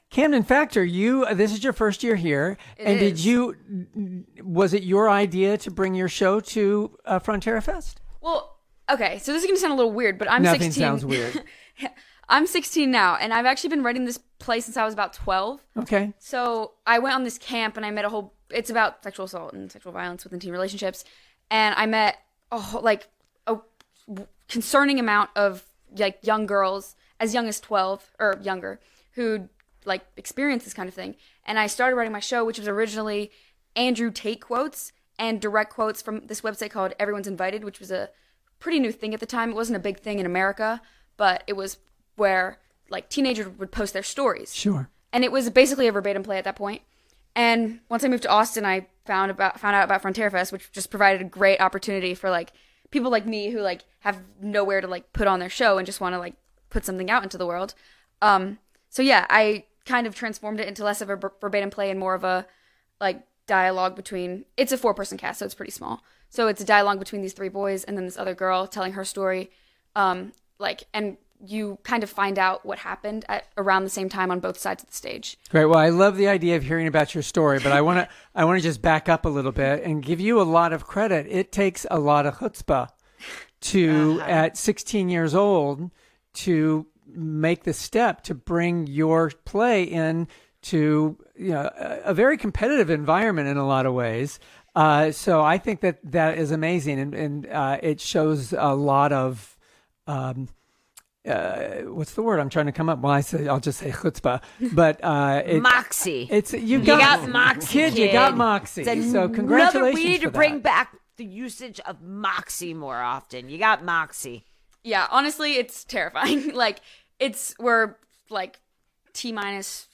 KOOP Radio Interview